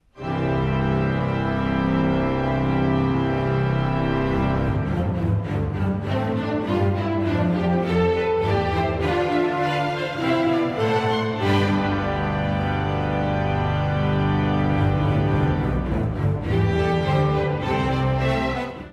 壮麗なオルガンが鳴り響く、唯一無二の交響曲——
厳かで、透明感のある響き。